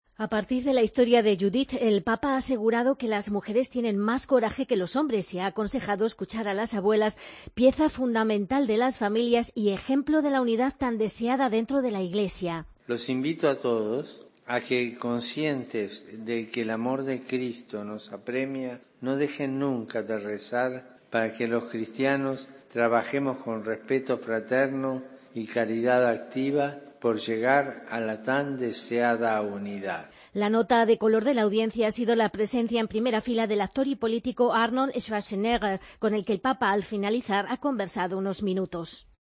El Papa afirma que "las mujeres son más valientes que los hombres" y "hay que escuchar a las abuelas". Crónica